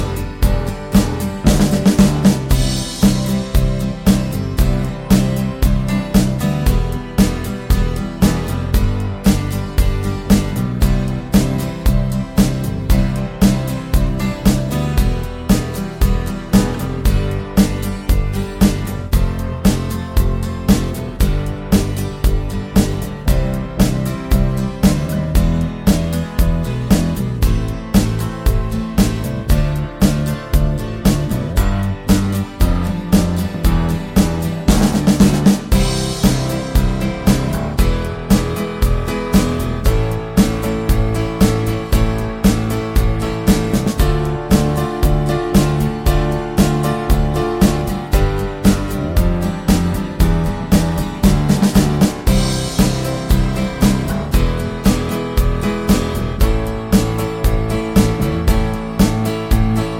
no Backing Vocals Soft Rock 3:27 Buy £1.50